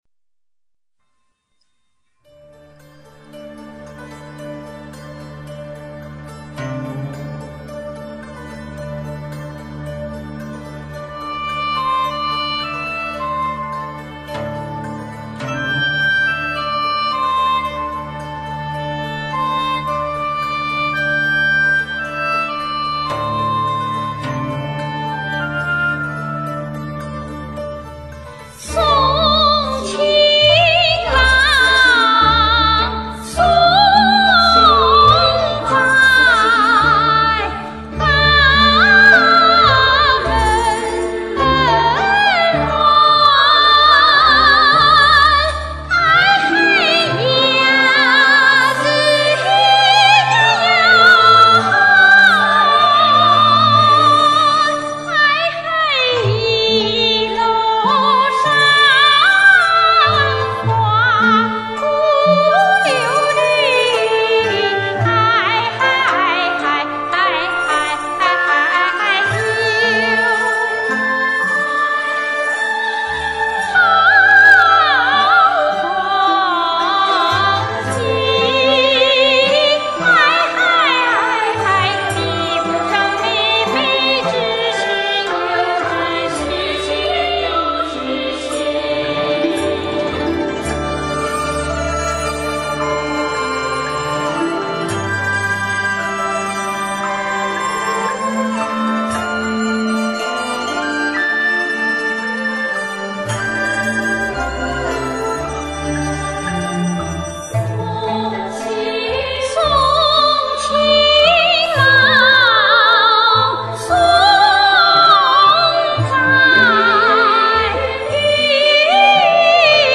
山西民歌《送情郎》
送上一曲俺年轻时唱的《送情郎》